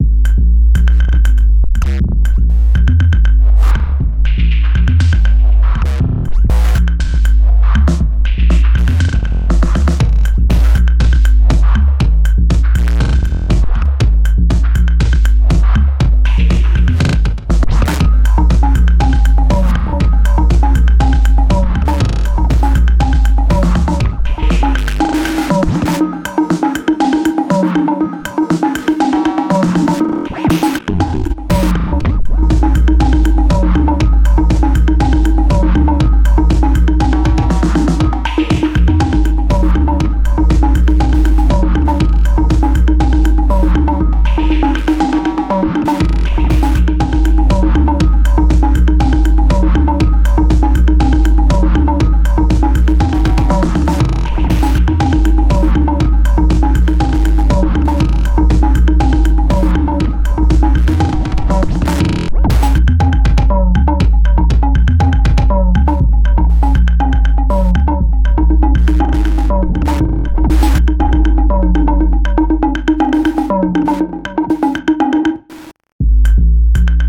Beat Battle!?
Das Pattern hat sowohl ne Bassline als auch verschiedene melodische Muster, Chords und alles kommt aus ner analogen Drum Maschine... widerspricht das jetzt den Kriterien? Anhang anzeigen MelodicDrumPattern.mp3 aber egal, ich wollte hier auch keine Diskussion lostreten...